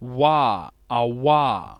28 w consonant approximant bilabial voiced [
voiced_bilabial_approximant.wav